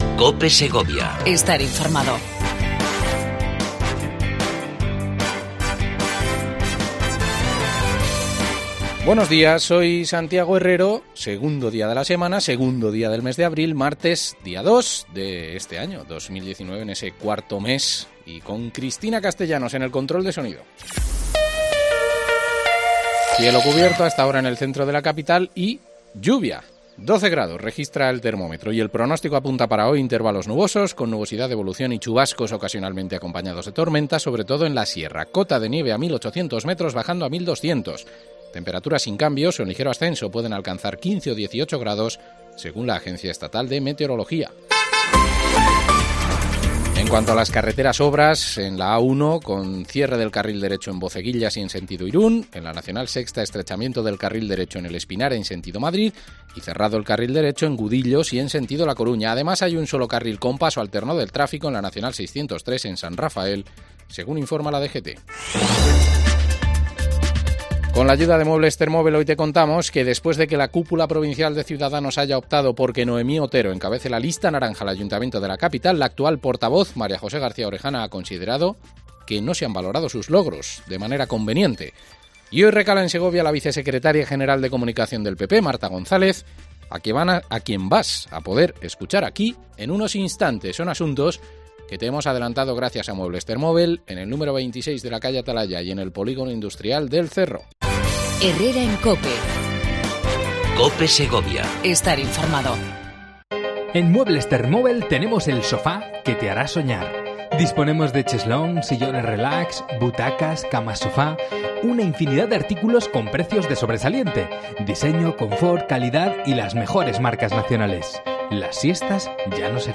Entrevista a Marta González, Vicesecretaria Nacional de Comunicación del PP. Muestra su apoyo a los candidatos que presenta el partido en próximos comicios